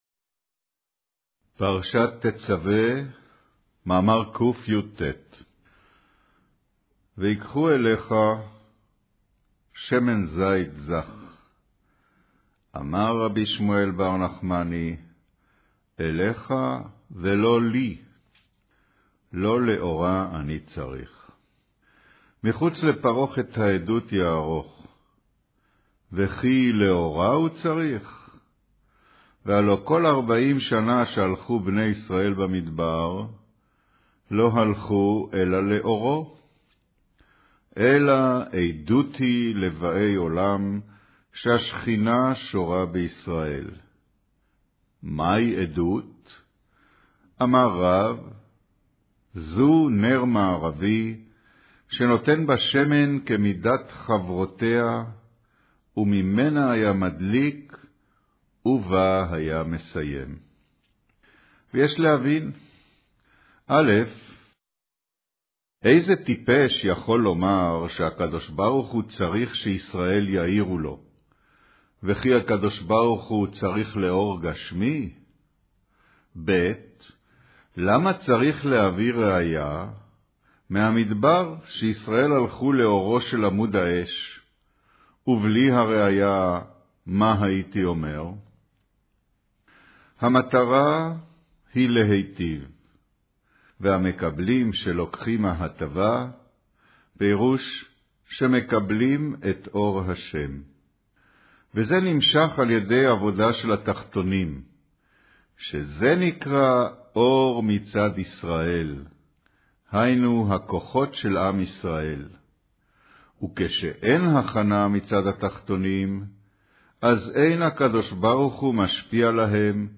קריינות פרשת תצוה, מאמר ויקחו אליך שמן זית זך